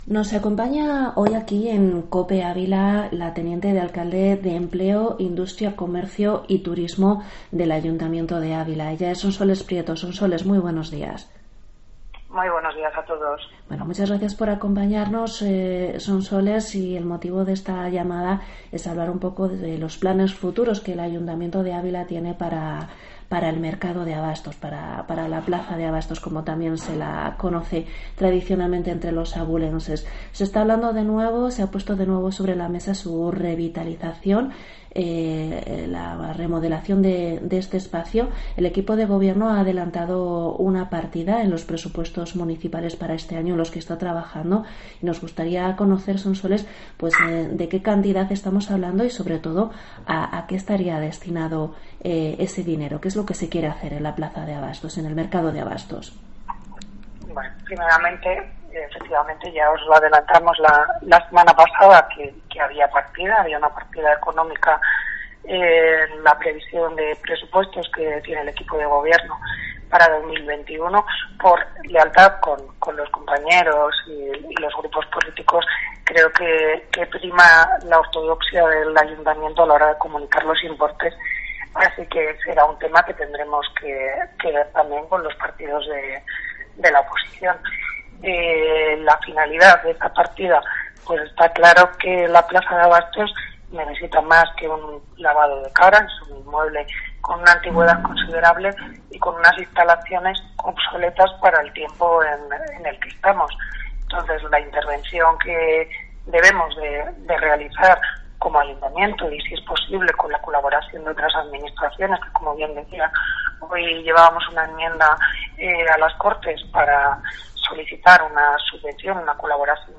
Entrevista teniente de alcalde, Sonsoles Prieto sobre el Mercado de Abastos